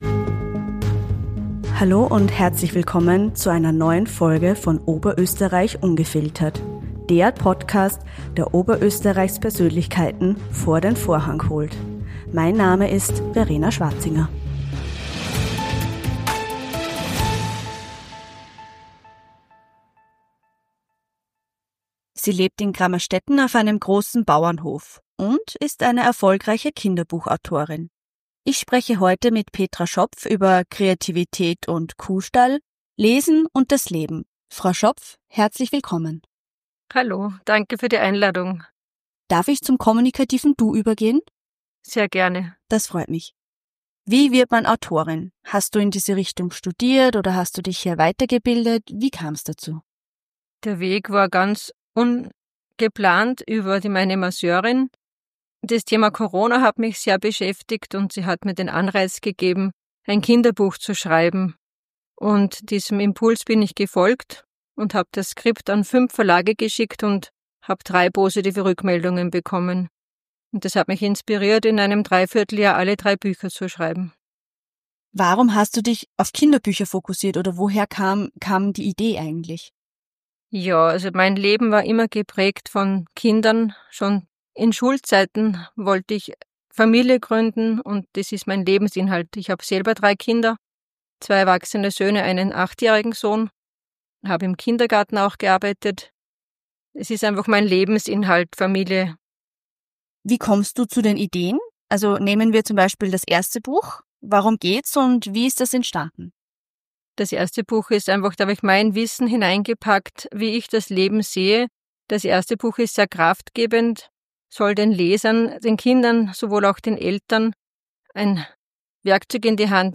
Abgerundet wird das Gespräch durch ihre ehrliche Reflexion über moderne Familienstrukturen und die Bedeutung von Vorleseritualen.